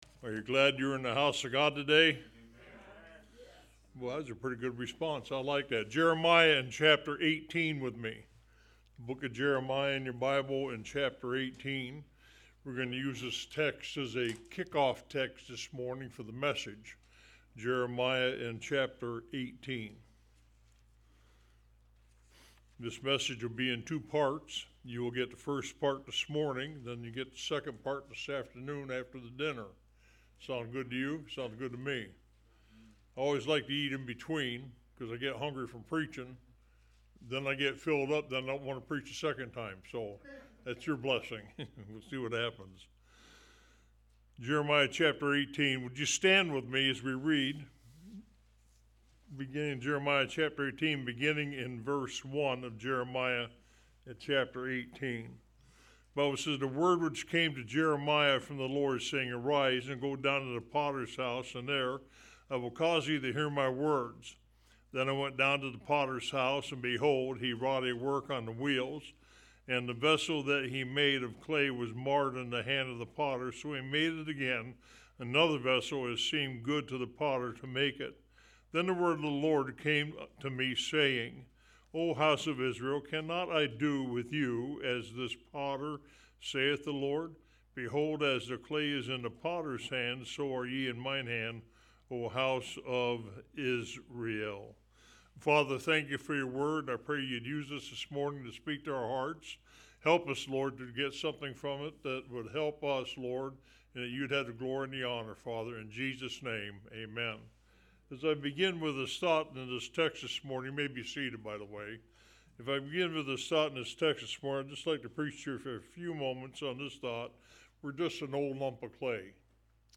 Online Sermons – Walker Baptist Church